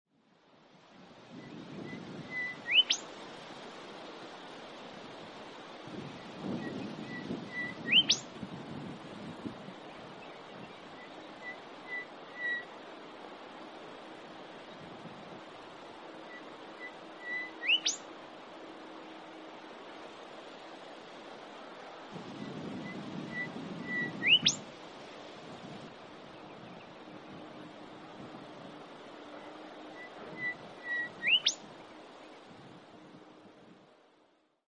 Golden Whistler - Pachycephala pectoralis
Voice: Melodious whi-whi-whi-whit, the last syllable like a whip-crack.
Call 1: whip-crack calls
Golden_Whistler.mp3